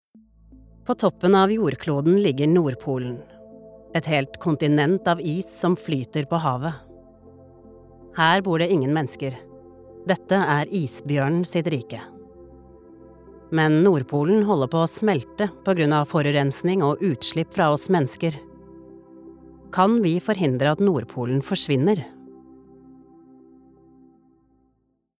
Documentary